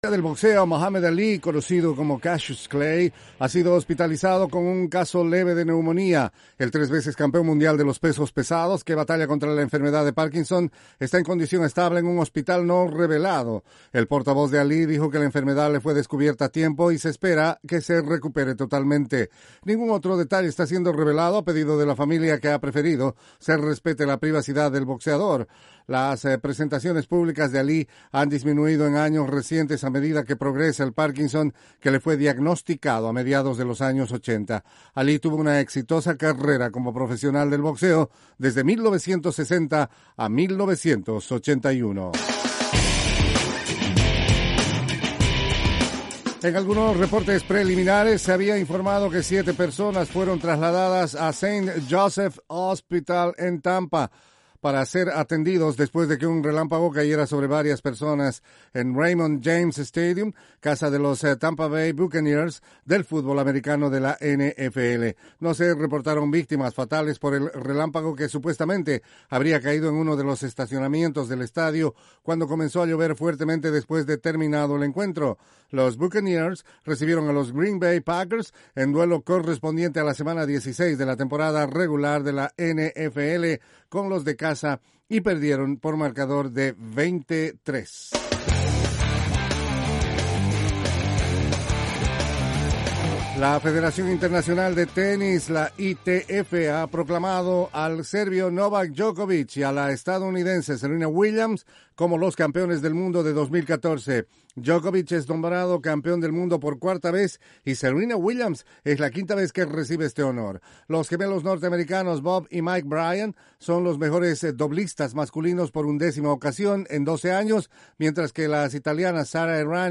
desde los estudios de la Voz de América